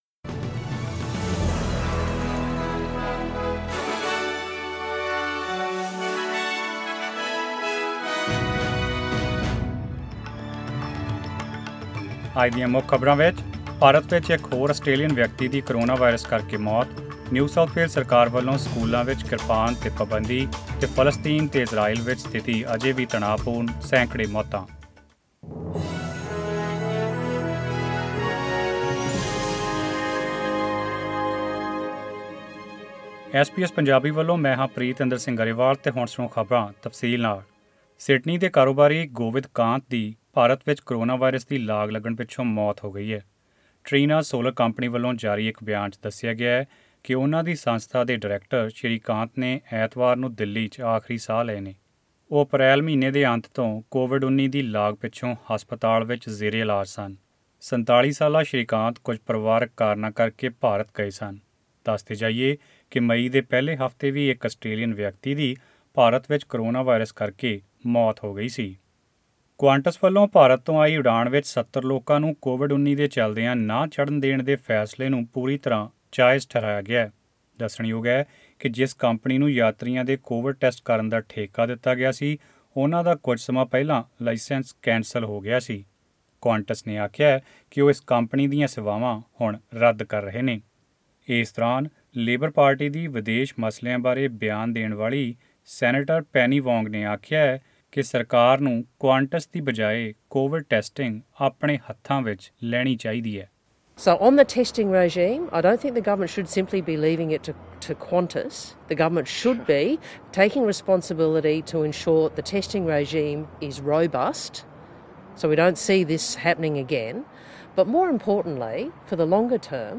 Carrying knives in NSW public schools will be banned as the government moves to close a loophole that allows members of the Sikh community to carry ceremonial daggers or 'Kirpans' for religious reasons. This news and more in tonight’s bulletin.